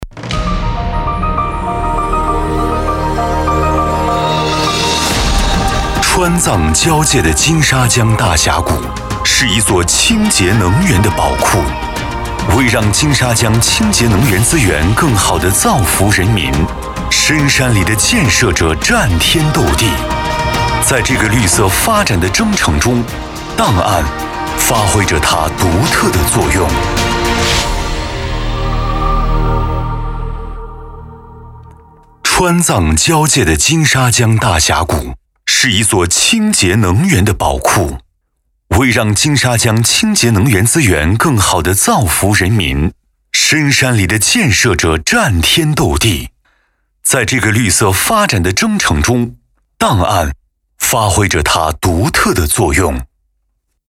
男62号配音师
声音浑厚大气低沉、擅长高端品牌广告、专题、宣传片等。